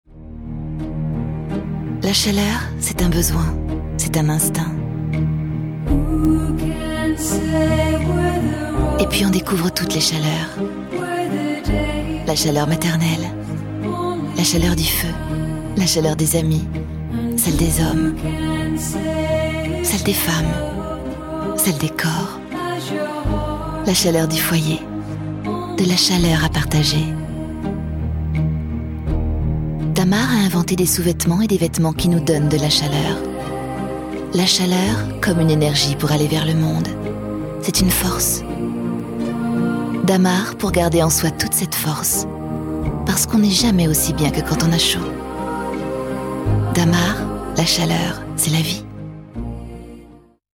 douce